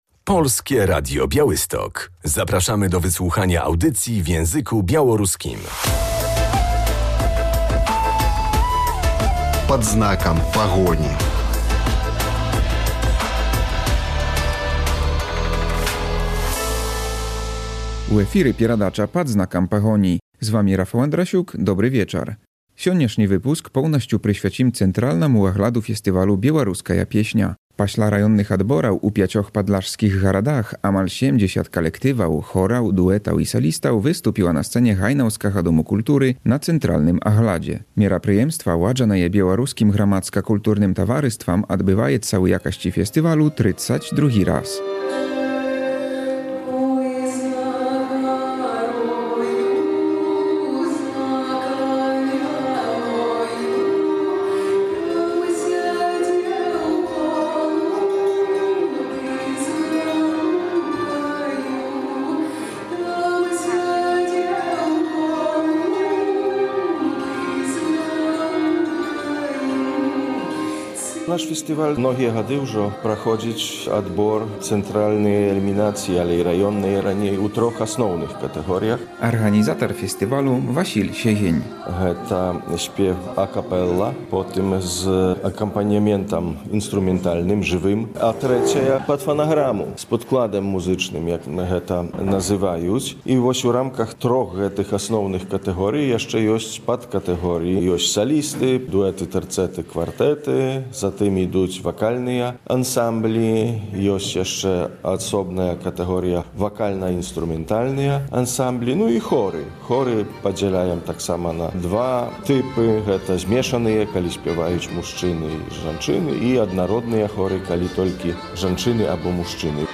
Relacja z eliminacji centralnych Ogólnopolskiego Festiwalu „Piosenka Białoruska 2025”.